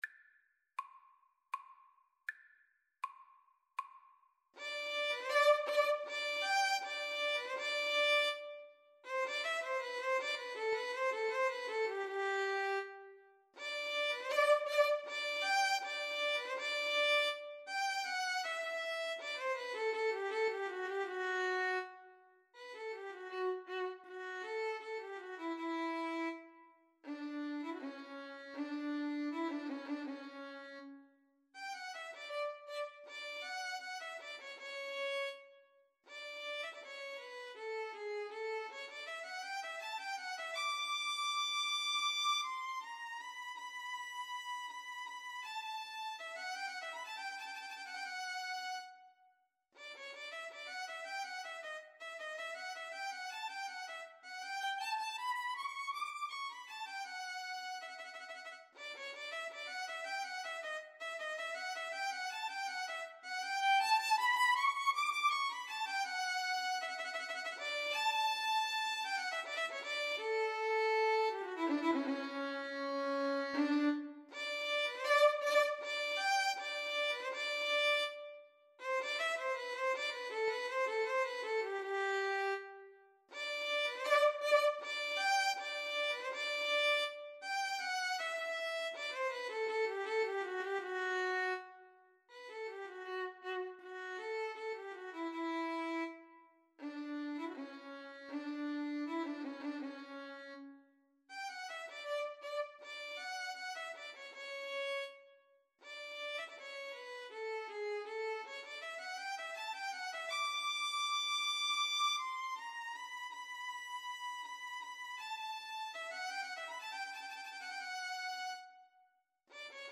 Free Sheet music for Violin-Cello Duet
G major (Sounding Pitch) (View more G major Music for Violin-Cello Duet )
Andante
3/4 (View more 3/4 Music)
Classical (View more Classical Violin-Cello Duet Music)